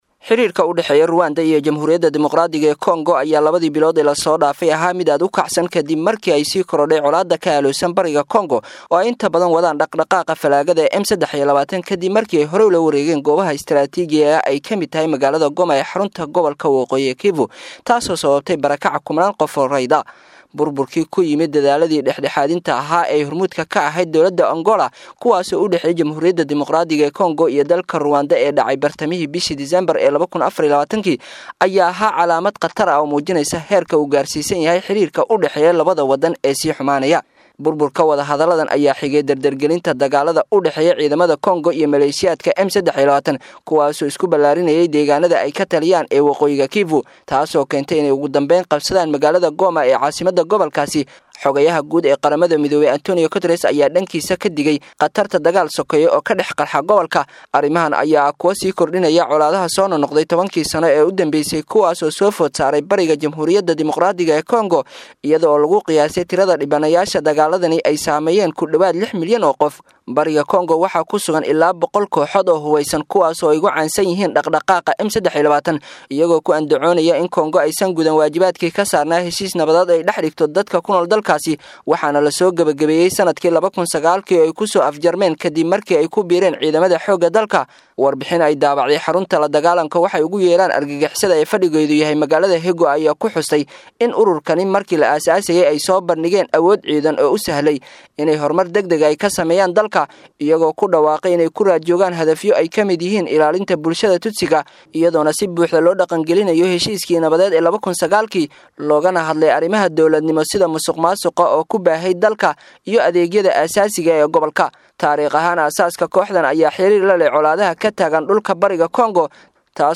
Warbixin_Muxuu_ku_Saleysan_Yahay_Dagaalka_ka_Dhaxeeya_Kooxda_M.mp3